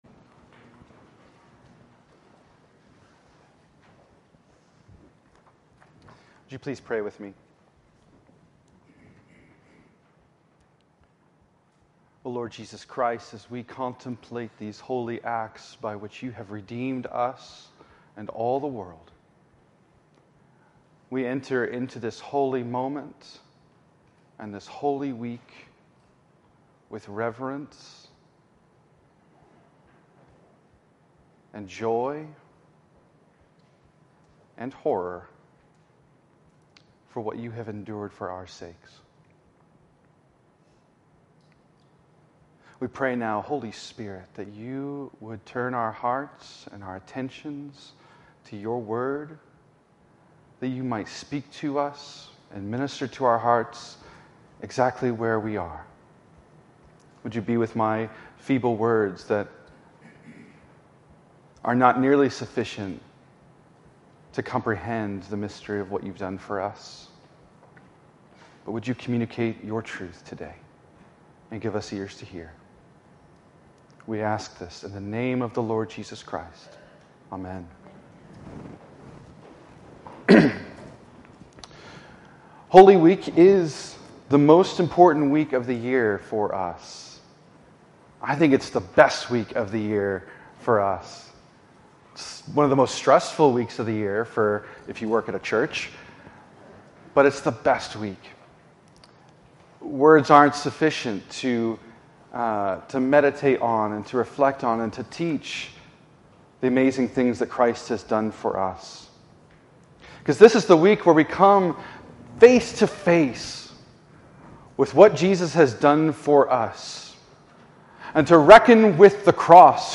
Save Audio In this sermon from Palm Sunday 2025